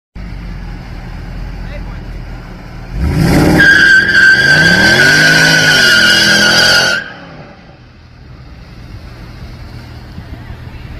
Звуки визга
Мерседес шлифует на месте